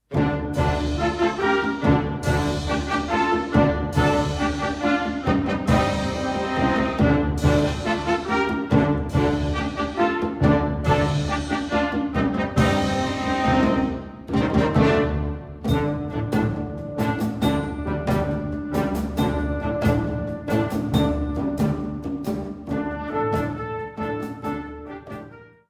Catégorie Harmonie/Fanfare/Brass-band
Sous-catégorie Musique de concert
Cngas